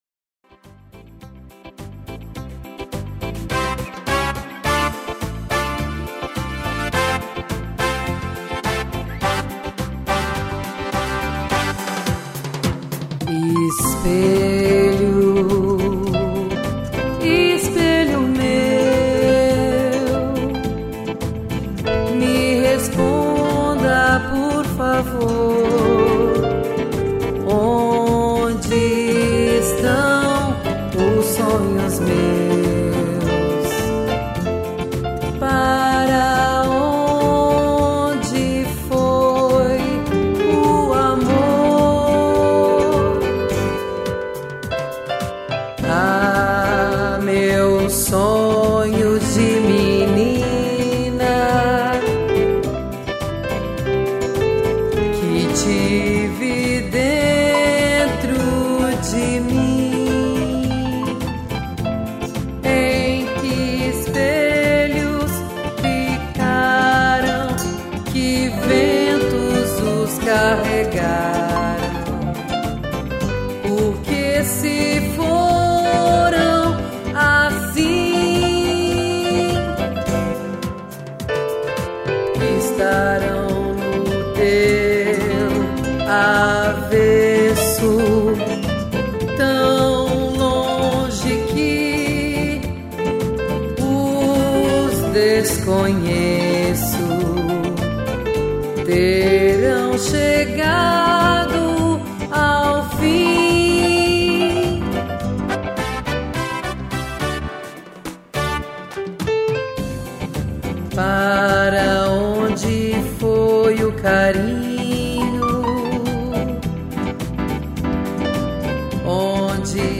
violão
piano